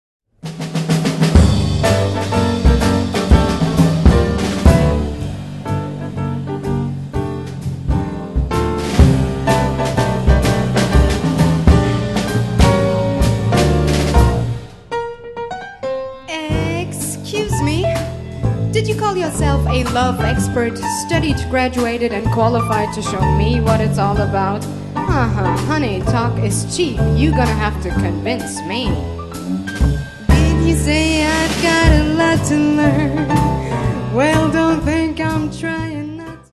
Gesang
Piano
Kontrabass
Schlagzeug